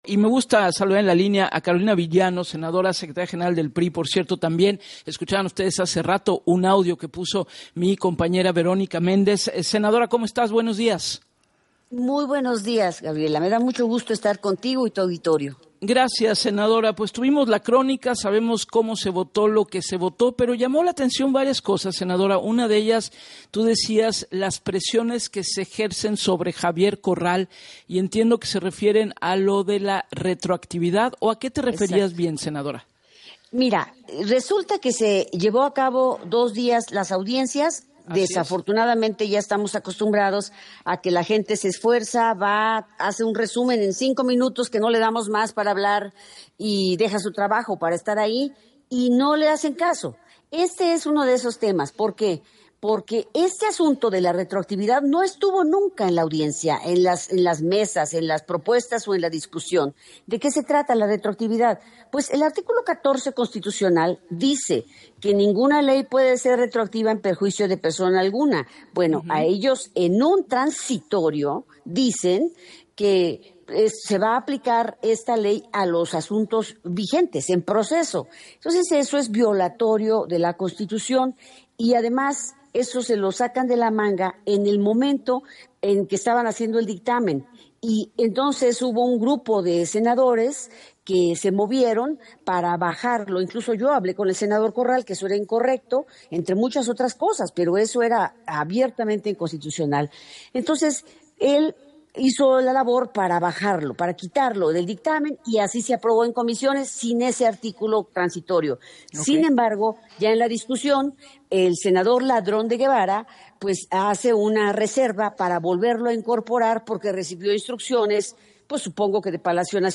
La reforma Ley de Amparo aprobada ayer en el Senado “es una reforma para proteger el poder… sobre todo con un gobierno como este que concentra el poder”, aseguró la senadora del PRI, Carolina Viggiano, quien dijo a Gabriela Warkentin y el espacio de “Así las Cosas”, que la retroactividad nunca estuvo en la discusión, se introdujo “porque quieren medidas recaudatorias porque están quebrados”.